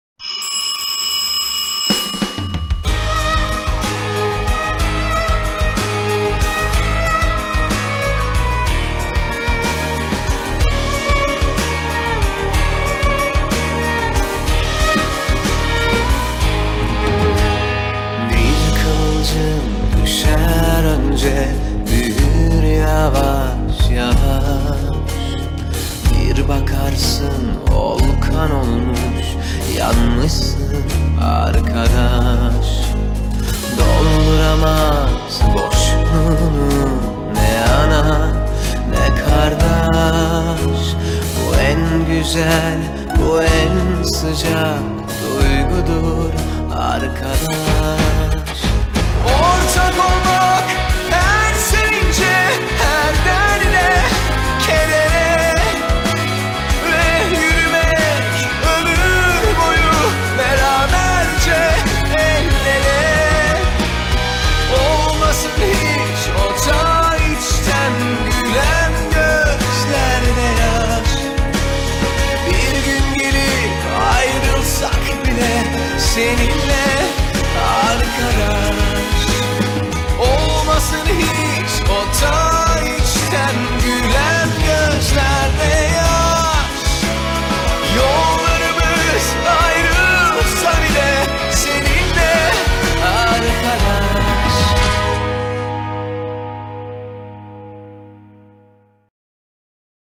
dizi müziği, duygusal hüzünlü rahatlatıcı fon müziği.